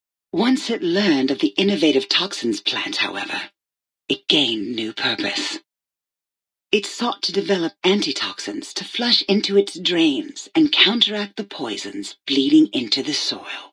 Category:Old World Blues endgame narrations Du kannst diese Datei nicht überschreiben.